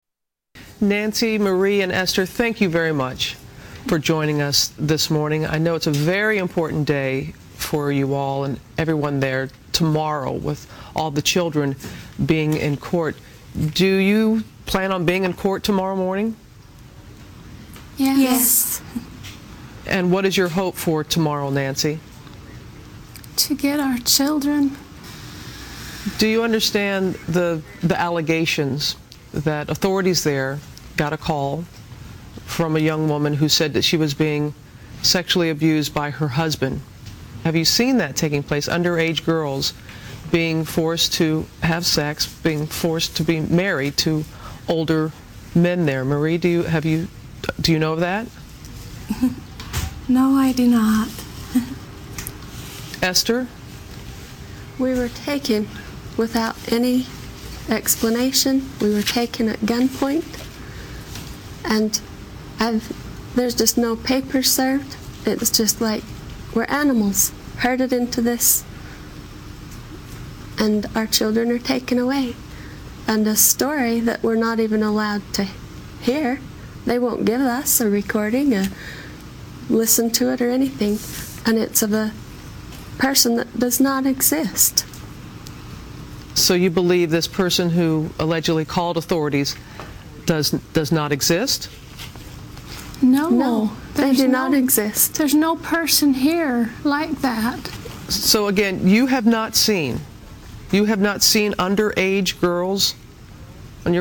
Polygamist mothers interview 1